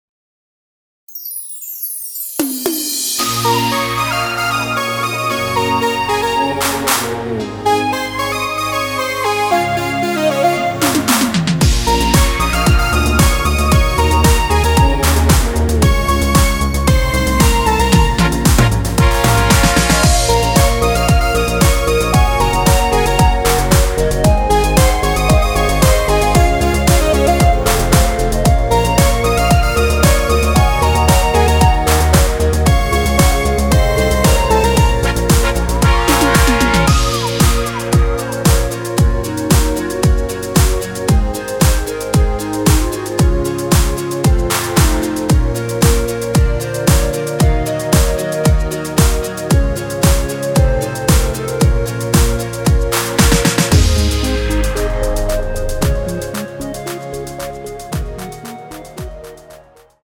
원키에서(-4)내린 멜로디 포함된 MR입니다.
Bbm
앞부분30초, 뒷부분30초씩 편집해서 올려 드리고 있습니다.
중간에 음이 끈어지고 다시 나오는 이유는